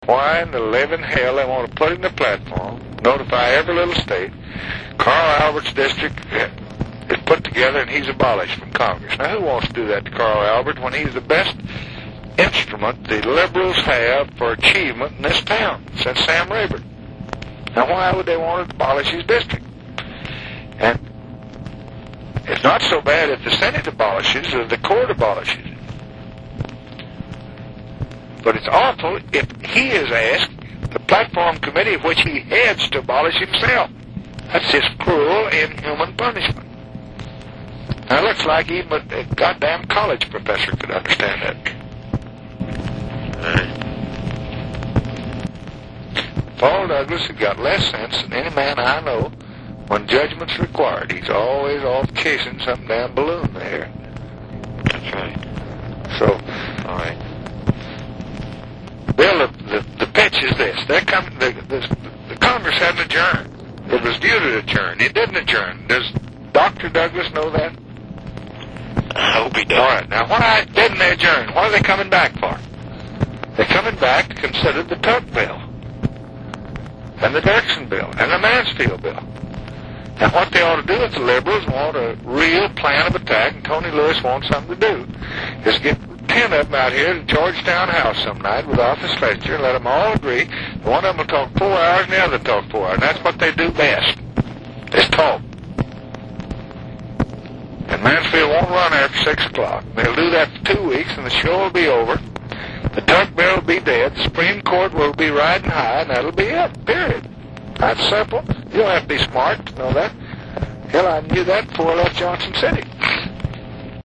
From Senate liberals,, the main problem came from Illinois senator Paul Douglas (a former economics professor at the University of Chicago) and Pennsylvania senator Joe Clark, who wanted a platform plank denouncing the Tuck bill, which sought to invalidate the Supreme Court’s one-man/one-vote ruling. Johnson fumed about the duo’s efforts in this August 21 call with Bill Moyers.